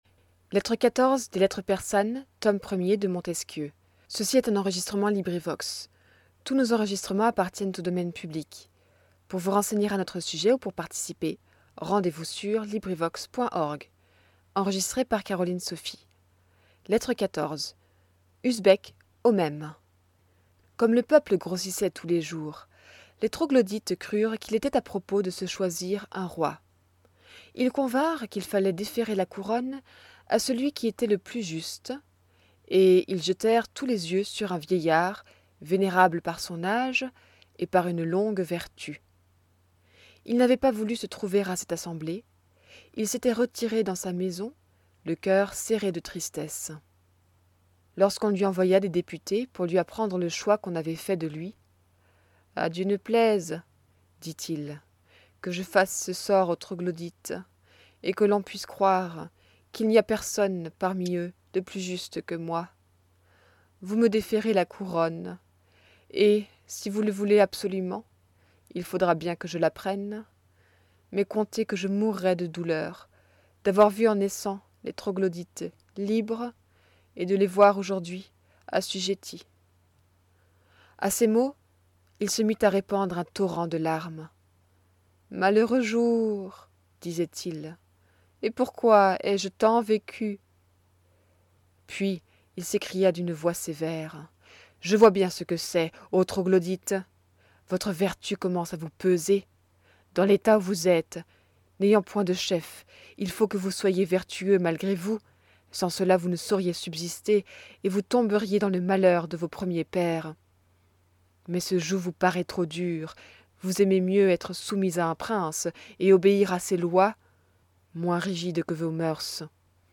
LibriVox recording by volunteers. Lettre 14. Usbek à Mirza .